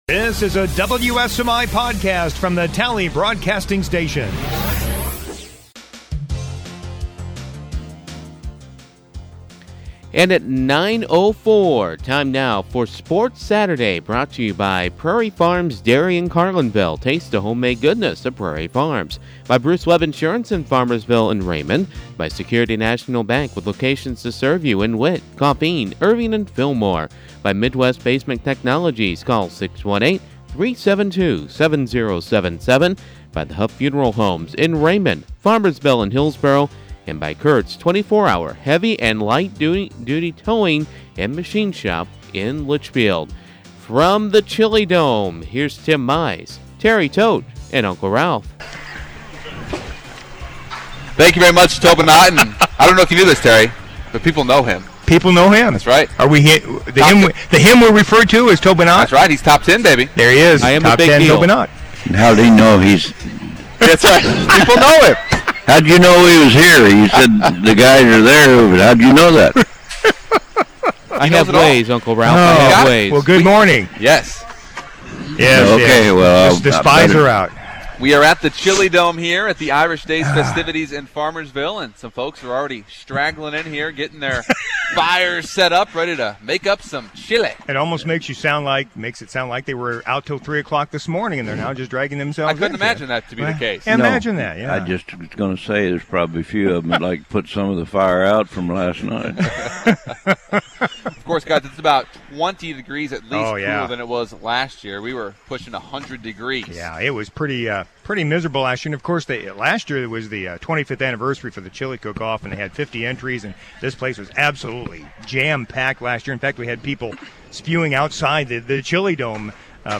LIVE from Irish Days in Farmersville